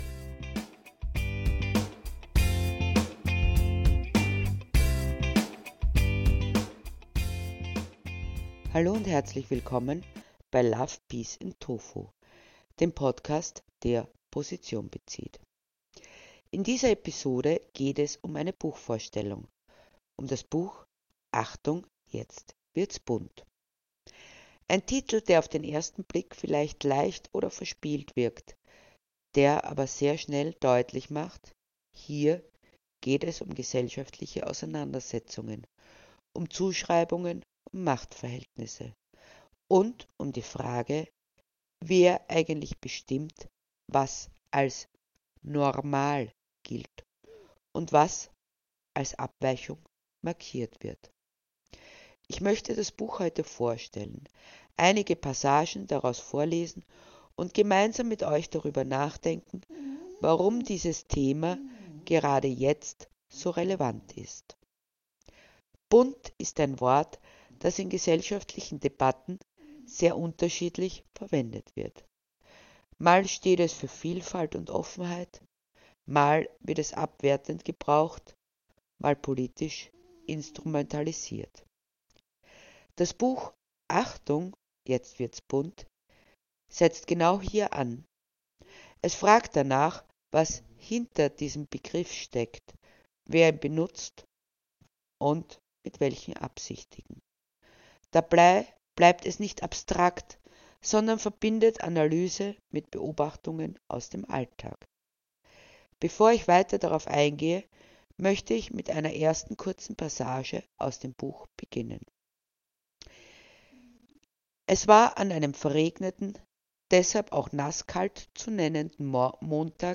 Buchvorstellung: Achtung!
Eine ruhige, reflektierende Buchvorstellung für alle, die Literatur als Denkanstoß verstehen.